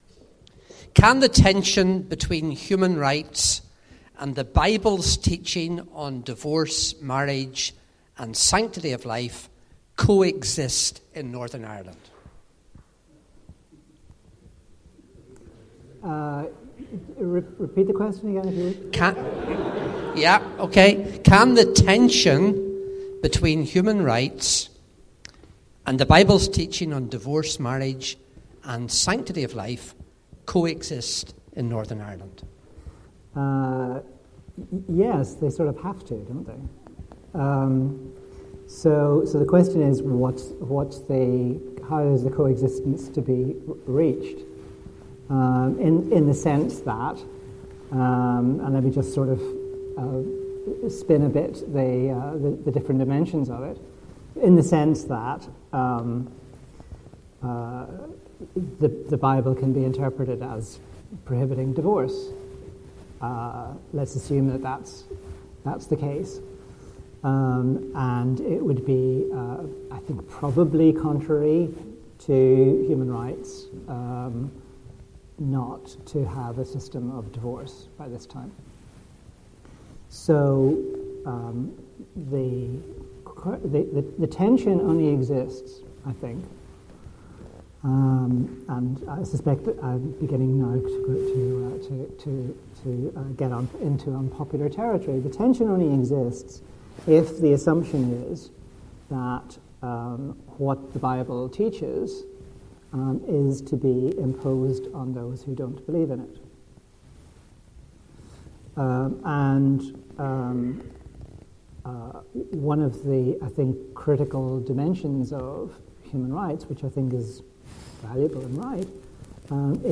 Seminar 6: Q & A